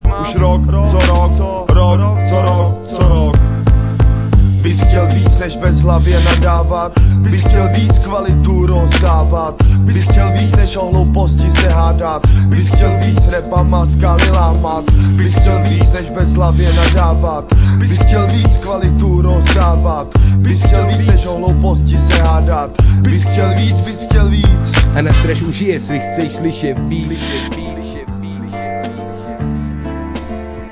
10 Styl: Hip-Hop Rok